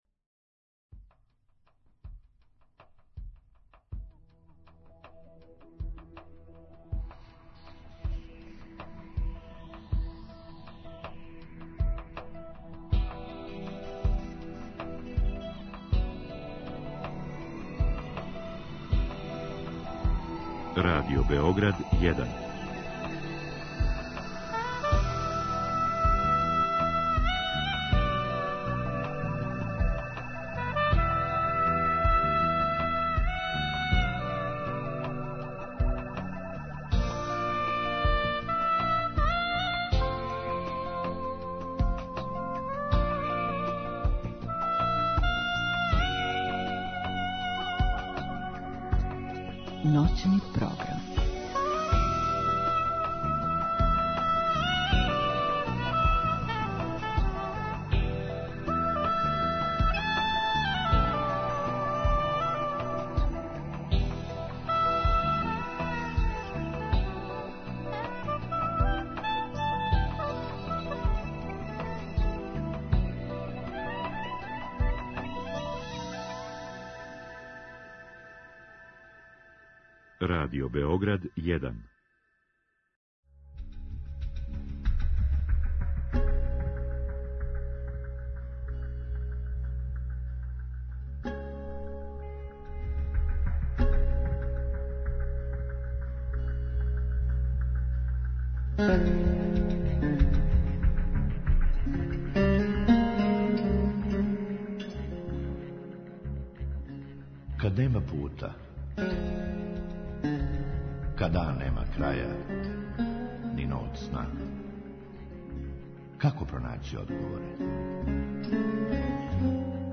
У другом сату слушаоци могу гошћи поставити питање директно у програму.